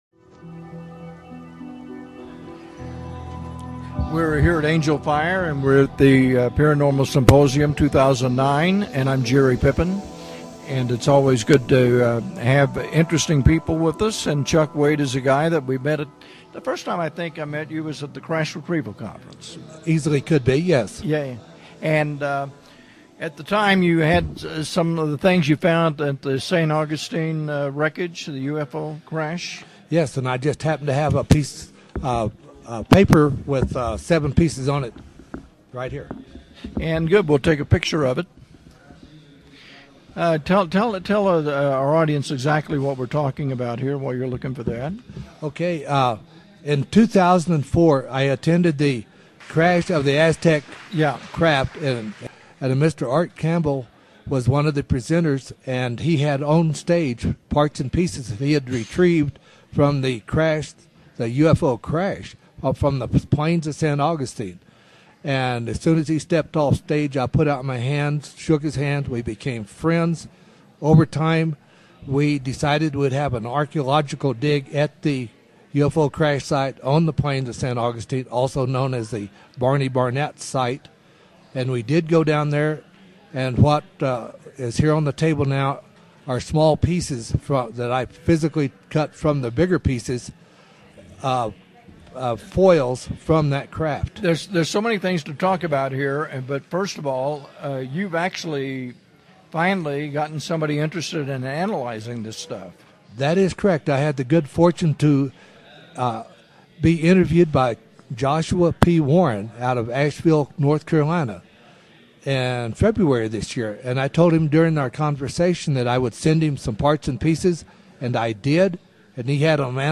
► 2010 Interview at the Angel Fire Resort- MP3 Running time: 27 minutes, 33 seconds Windows Media Version ►2009 Interview at the Angel Fire Community Center- MP3 Runnung time: 27 minutes, 34 seconds Windows Media Version